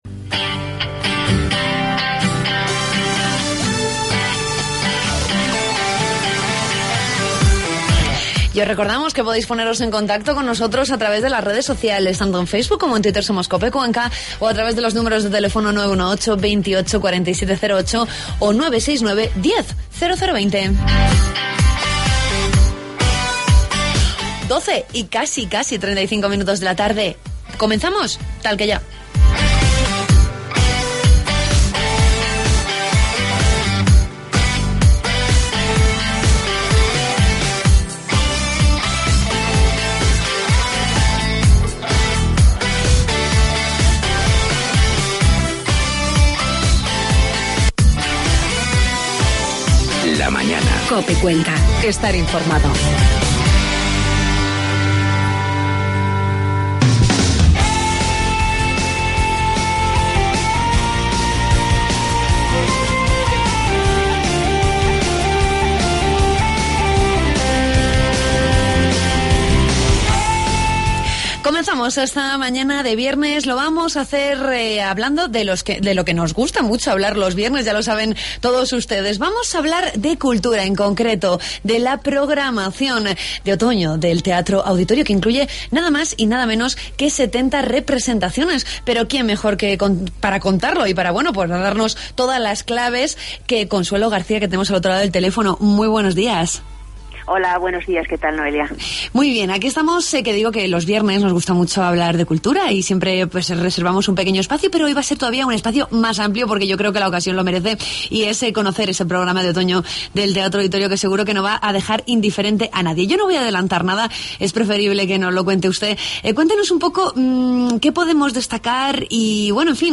Entrevistamos a la concejala de Cultura, Consuelo García, con la que conocemos la nueva programación del Teatro Auditorio.